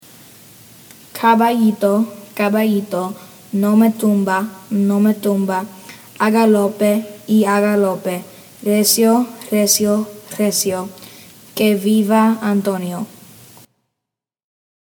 Caballito - Pronunciation